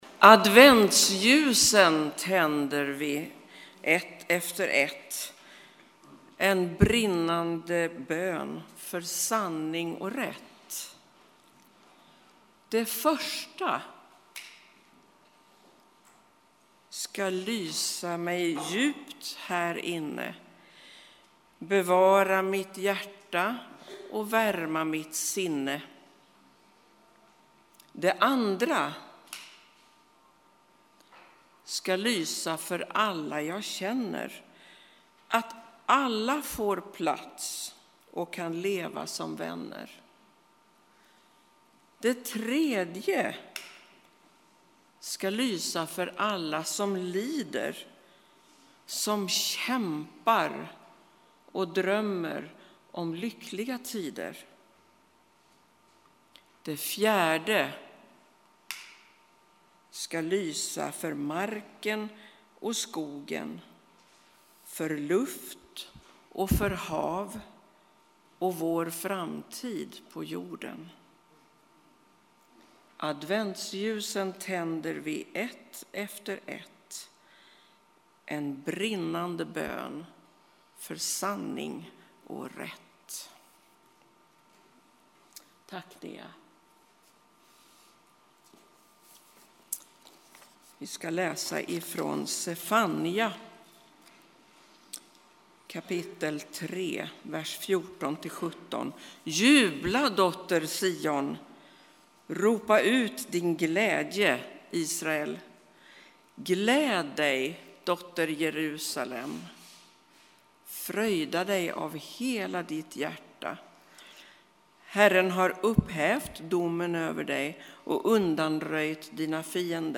Textläsning, psalmsång och predikan